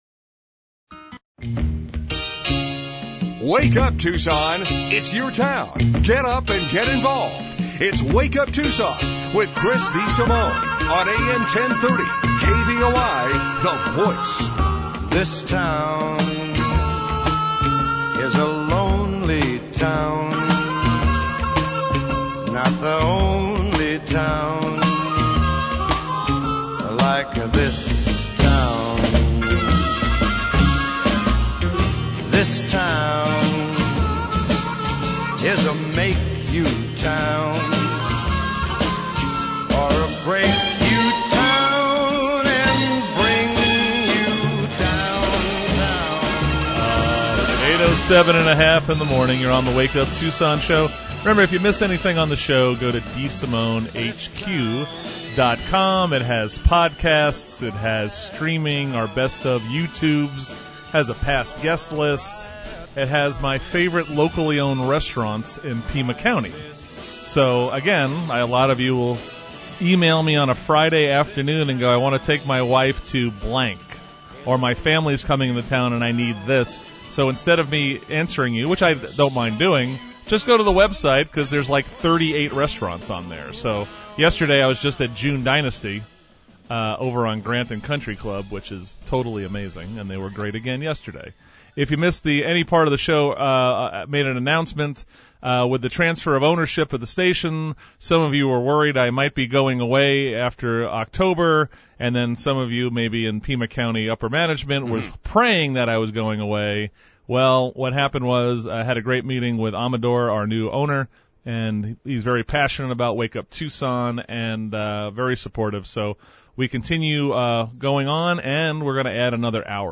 Me, and radio: Wake up Tucson